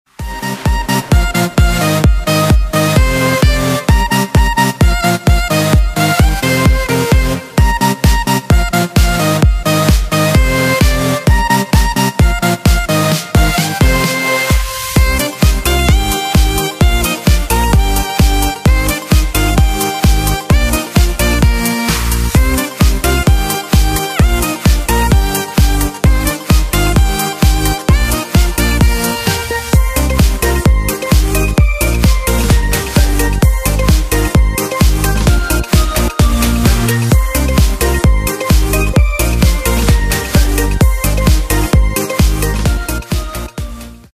• Качество: 128, Stereo
громкие
dance
Electronic
EDM
без слов
tropical house
Саксофон
Big Room
electro house